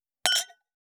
302チーン,カラン,キン,コーン,チリリン,カチン,チャリーン,クラン,カチャン,クリン,シャリン,チキン,コチン,カチコチ,チリチリ,シャキン,カランコロン,パリーン,ポリン,トリン,
コップ効果音厨房/台所/レストラン/kitchen食器
コップ